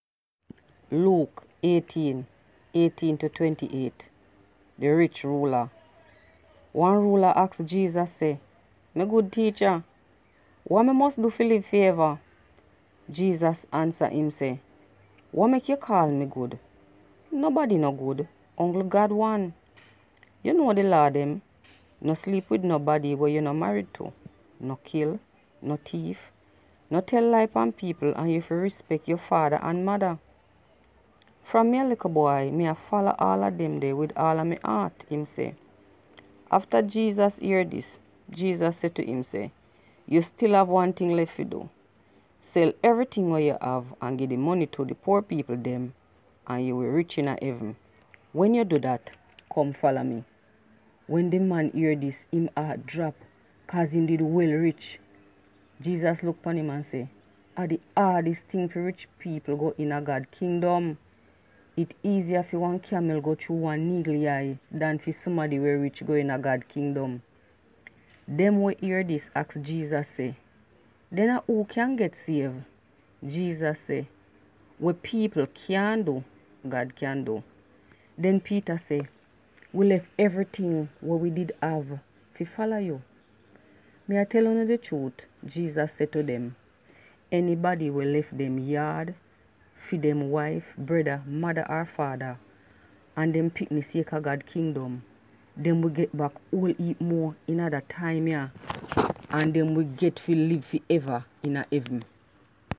Today we present to you excerpts from the Holy Bible in the Jamaican language Patois (pronunciation patwa) or Jamaican Creole.